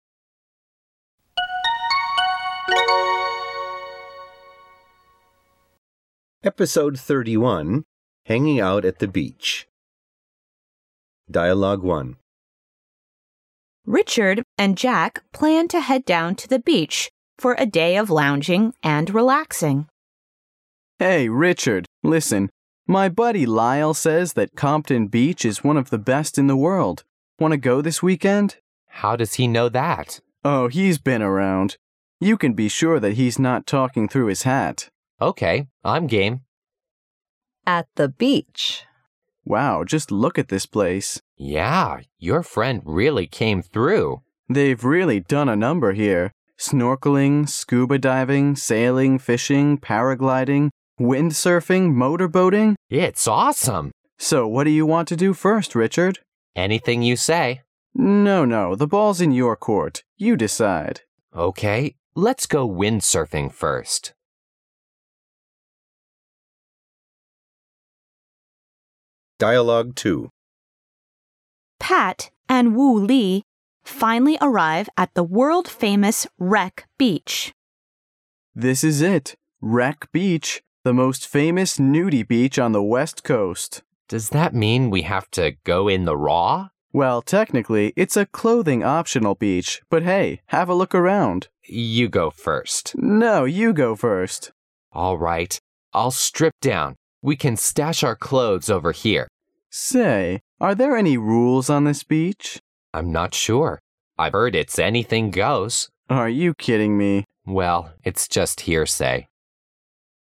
它设计了60个场景，每个场景包含两组对话，内容涉及众多日常生活场景和工作场景，对话语言地道新潮，相关文化背景知识介绍，让你将文化学习与语言学习融为一体，如同亲临现代美国社会，全方位学习地道的英语口语表达。